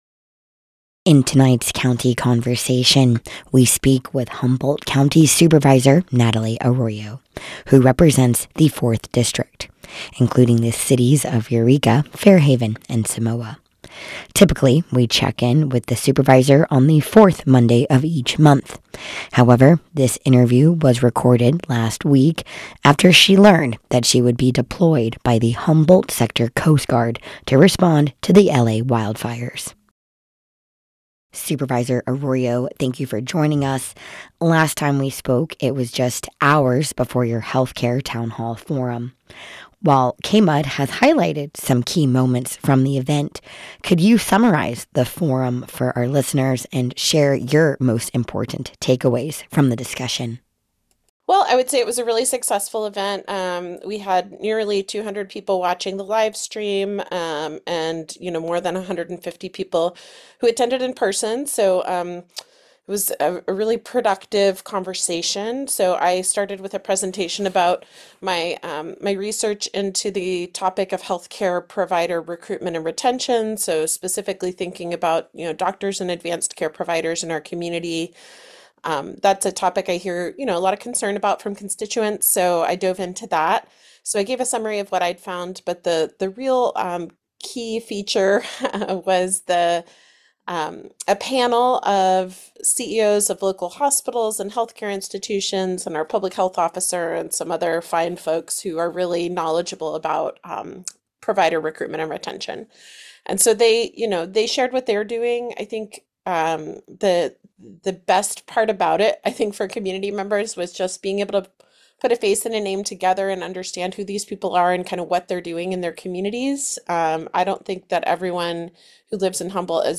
County Conversation: A Discussion with Humboldt's 4th District Supervisor Natalie Arroyo – February 2025